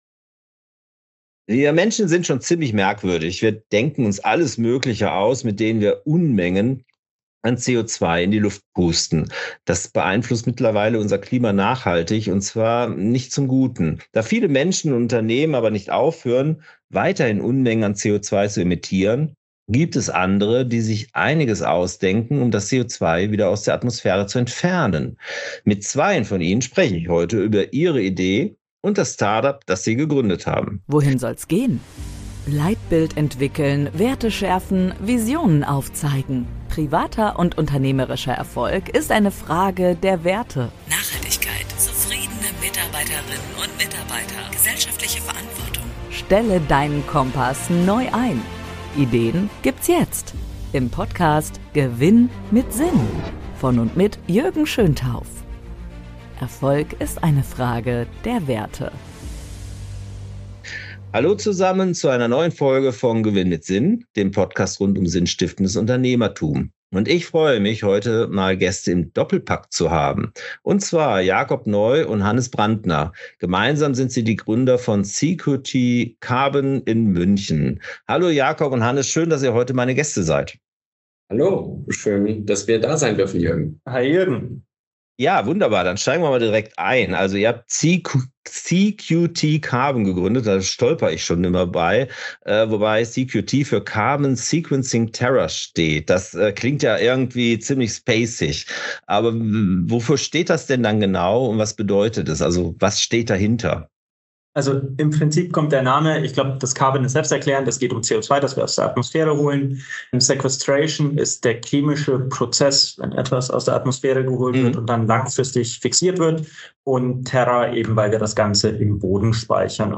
Da viele Menschen und Unternehmen aber nicht aufhören, weiterhin Unmengen an CO₂ zu emittieren, gibt es andere, die sich etwas ausdenken, um das CO₂ wieder aus der Atmosphäre zu entfernen. Mit zweien von ihnen spreche ich heute über ihre Idee und das Start-up, das sie gegründet haben.